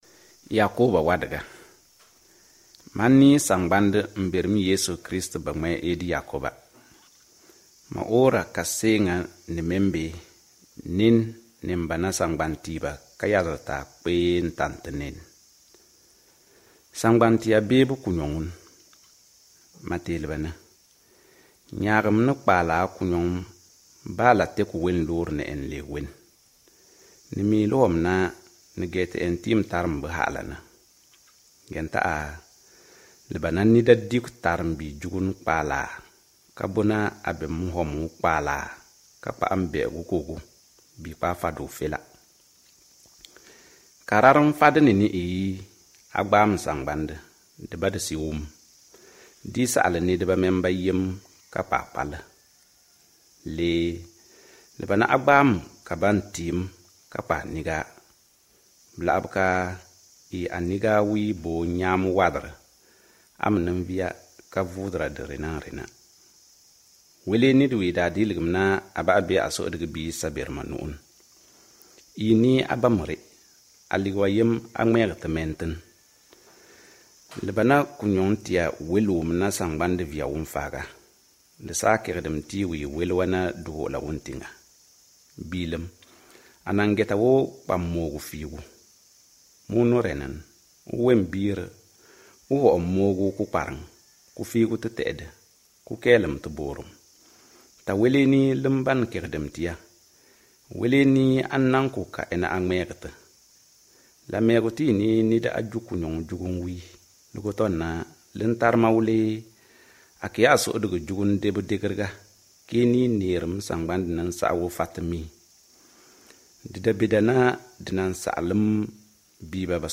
Ecoutez la lecture du livre de Jacques | Nawdm reda wiingan
Ici vous pouvez écouter la lecture du livre de Jacques tout entier.